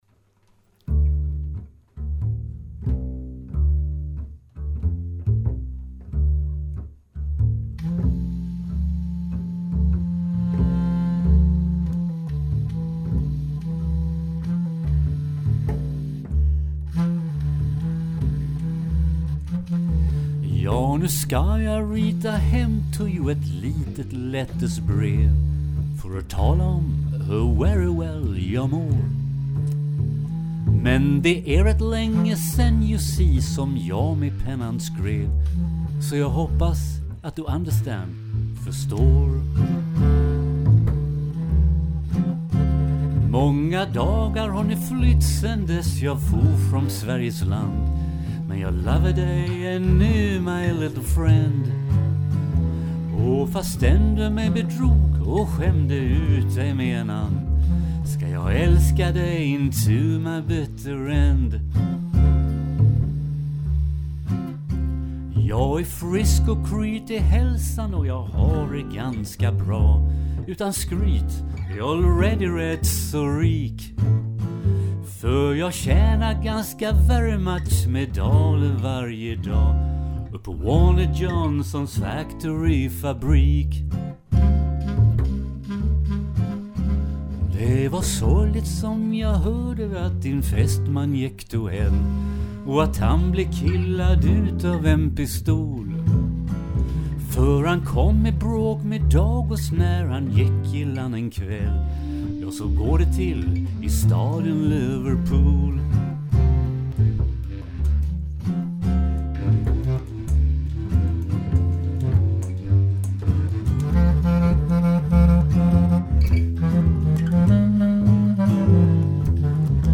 sång och gitarr
kontrabas
basklarinett, altflöjt och saxofon.